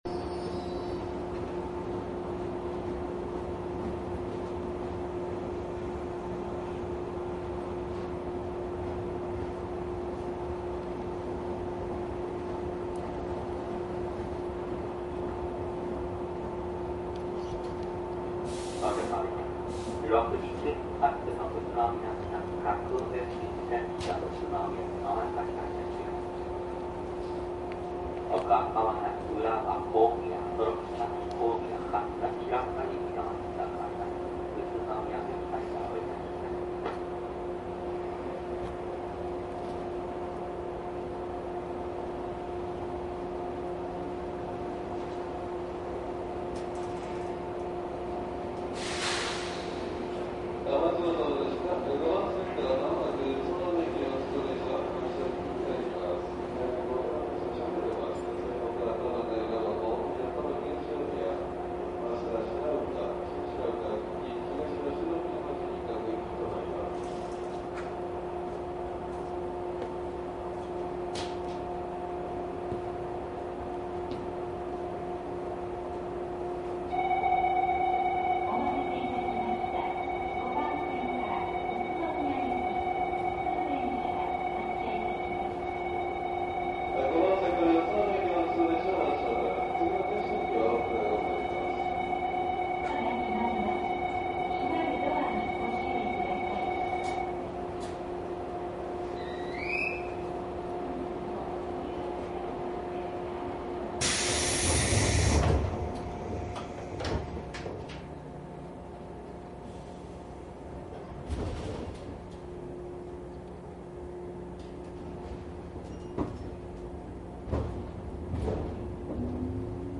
JR宇都宮線 115系 走行音  ＣＤ♪
JR宇都宮線 115系で走行音を録音したCDです
■【普通】上野→久喜→宇都宮 モハ115－332＜DATE98-4-2＞
DATかMDの通常SPモードで録音（マイクＥＣＭ959）で、これを編集ソフトでＣＤに焼いたものです。